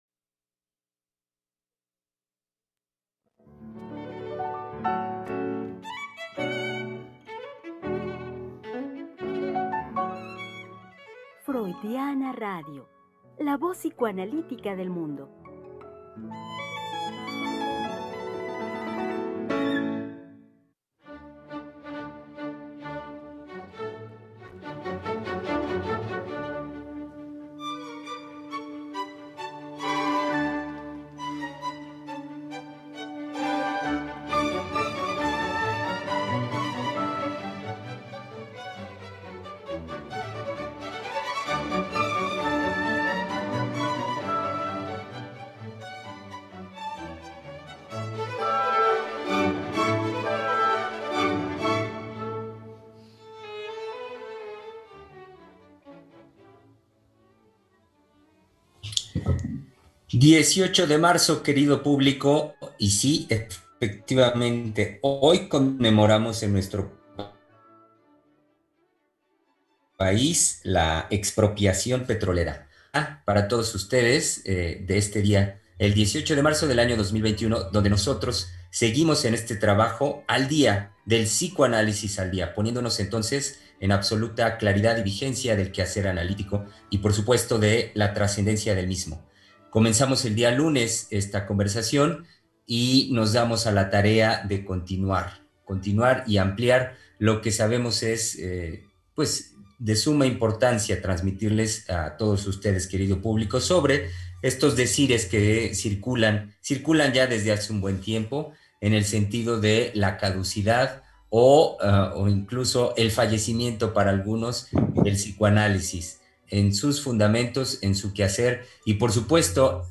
Tres Mujeres Psicoanalistas Hablando de la Vida Cotidiana